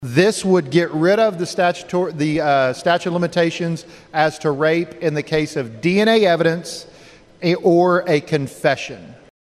CLICK HERE to listen to commentary from House Sponsor, Representative Jon Echols.